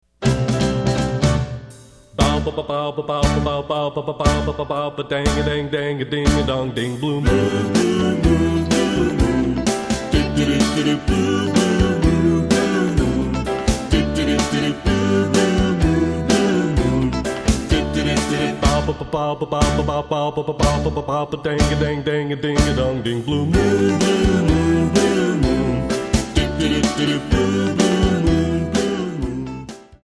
MPEG 1 Layer 3 (Stereo)
Backing track Karaoke
Pop, Oldies, 1960s